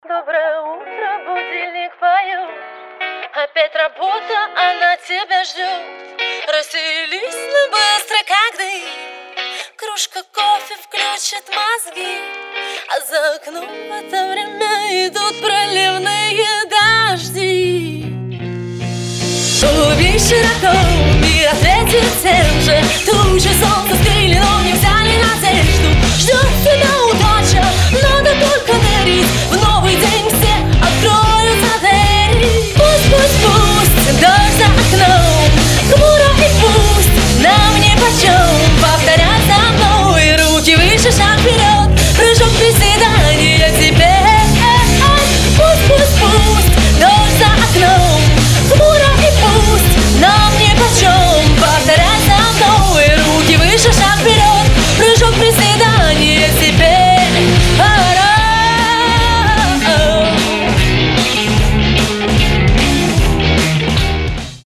• Качество: 320, Stereo
позитивные
мотивирующие
заводные
красивый женский вокал
легкий рок
бодрящие